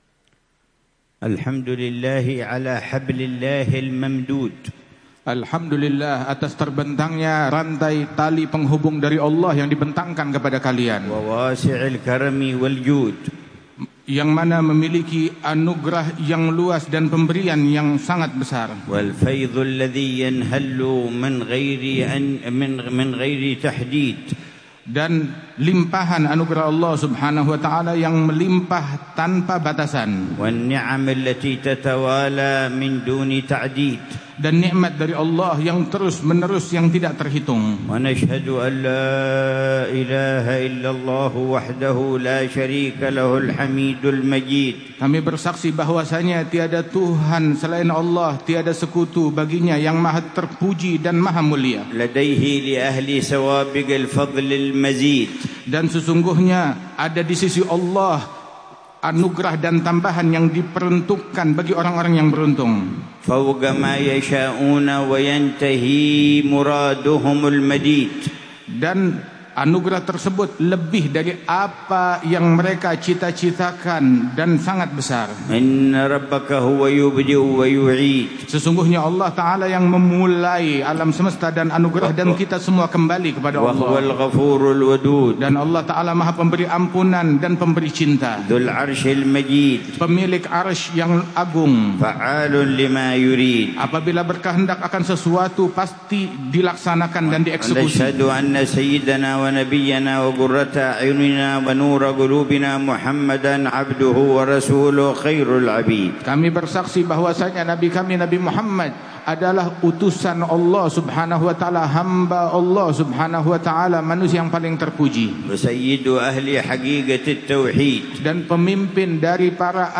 محاضرة في معهد الفخرية في جاكرتا 1447هـ
محاضرة العلامة الحبيب عمر بن محمد بن حفيظ في معهد الفخرية في العاصمة الإندونيسية جاكرتا، ليلة الإثنين 28 ربيع الثاني 1447هـ